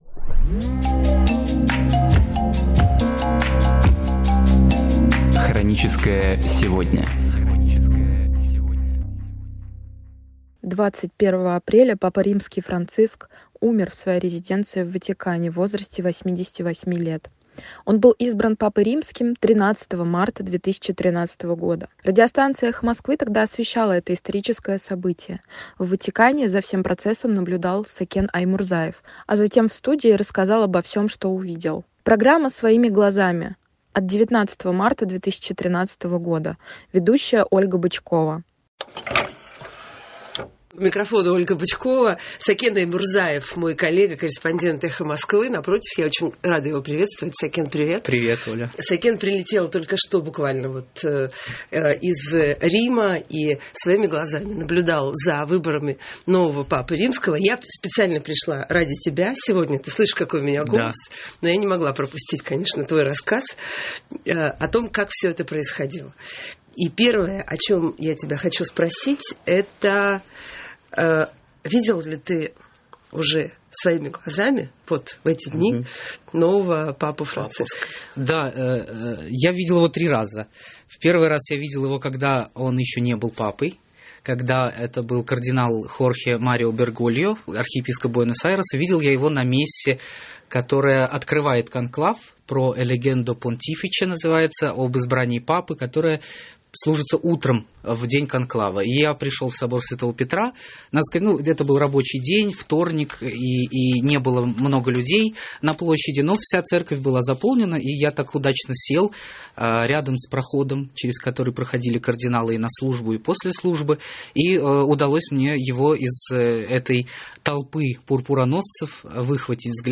Архивные передачи «Эха Москвы» на самые важные темы дня сегодняшнего